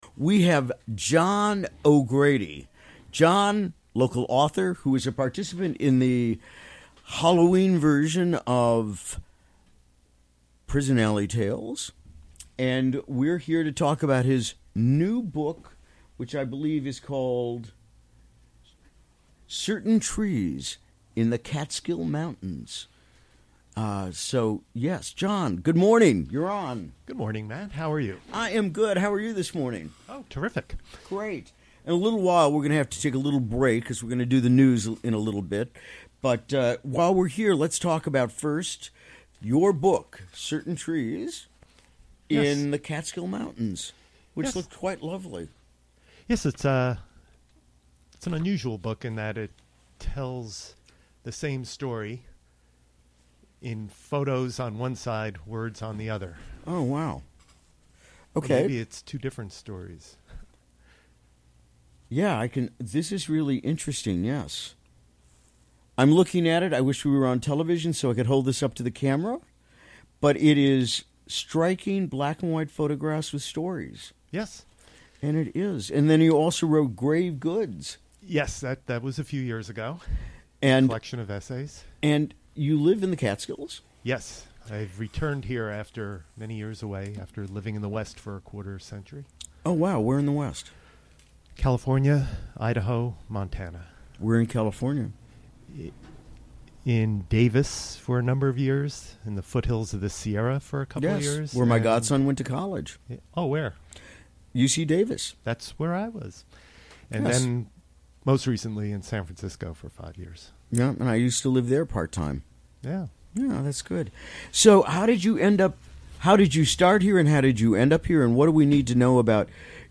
Recorded during the WGXC Morning Show of Wednesday, Dec. 6, 2017.